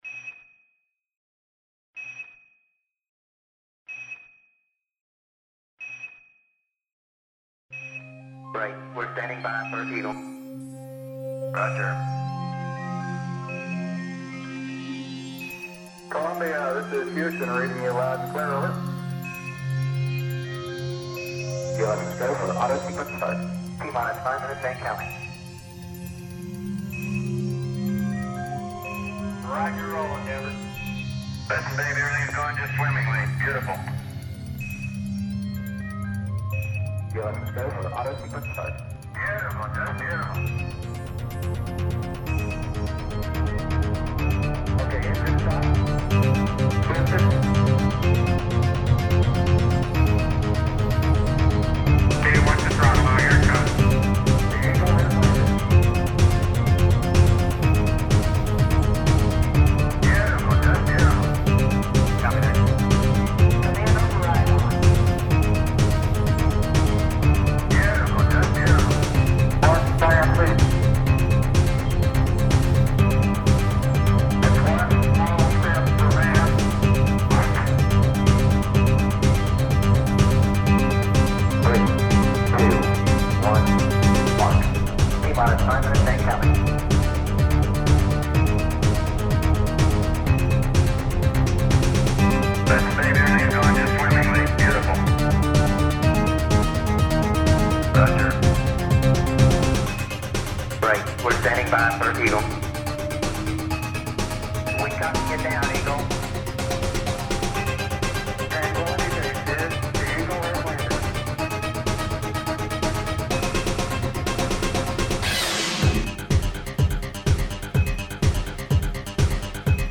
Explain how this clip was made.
These tunes are the result of some fiddling I did with a mod sequencer a few years back. I don't have access to the studio gear necessary to lay down tracks as I think of them, so the cheap route was my only option.